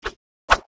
swing_1.wav